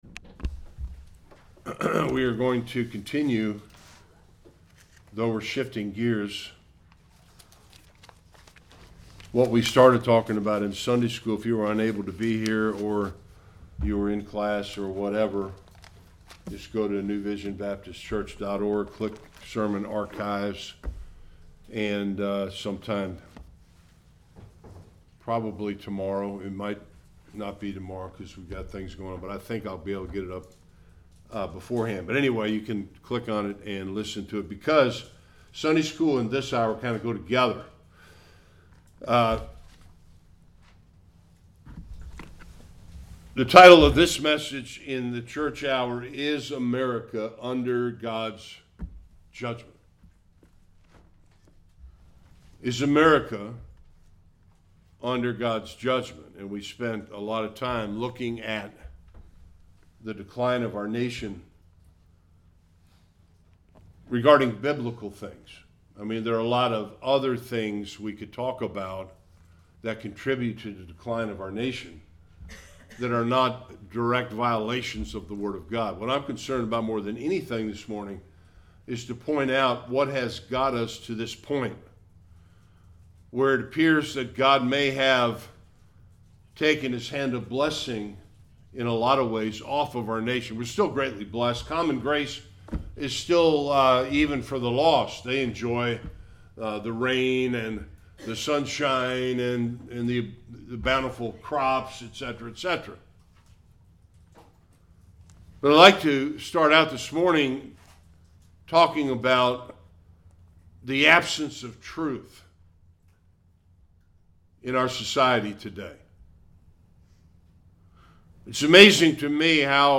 Romans 1 Service Type: Sunday Worship Is America under God’s wrath of abandonment?